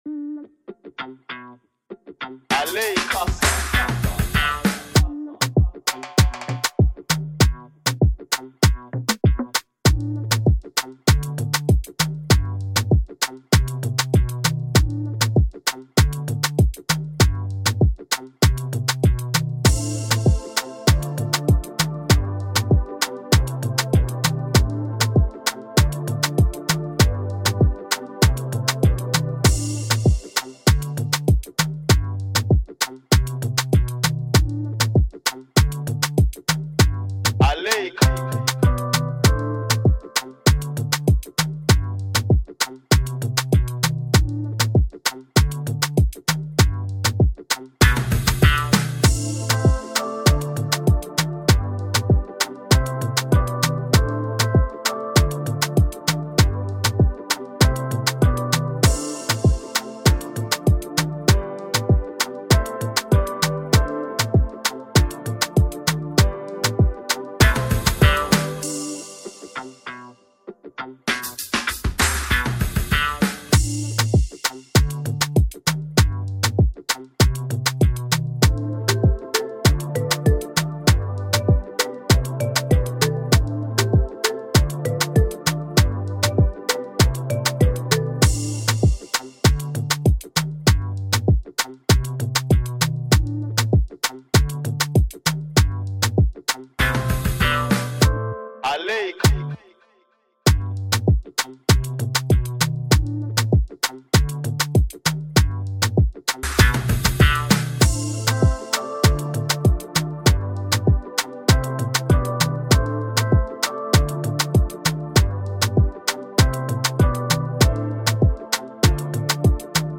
remake free beat instrumental